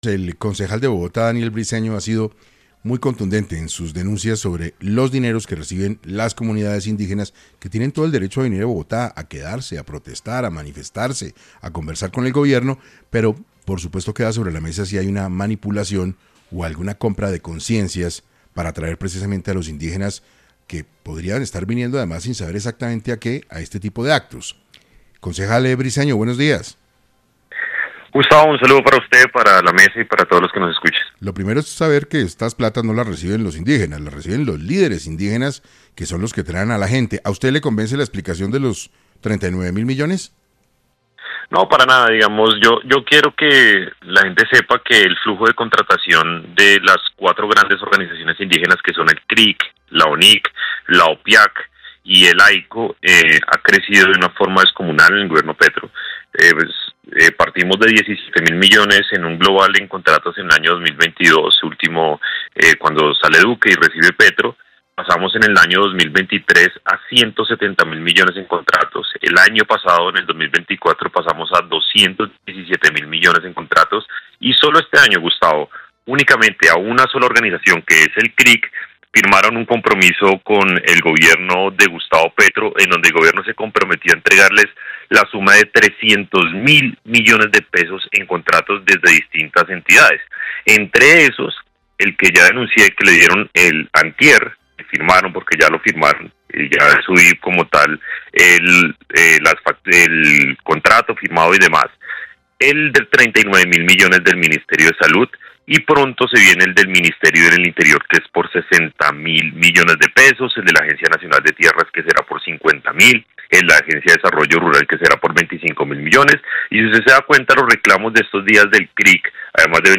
Daniel Briceño, concejal de Bogotá, habló en 6AM sobre la situación de los indígenas en la ciudad, sus contrataciones y los dineros entregados a estas comunidades.